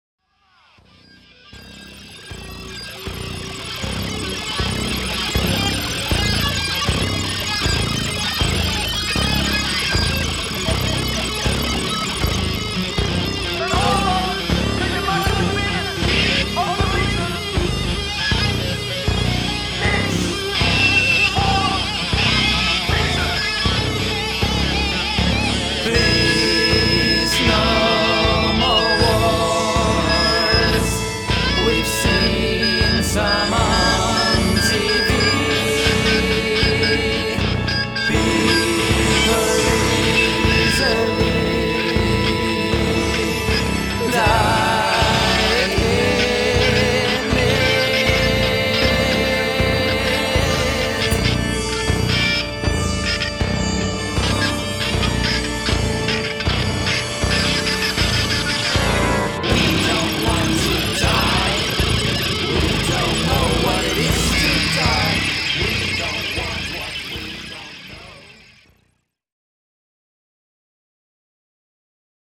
is a more loosely composed and/or improvised work of sound.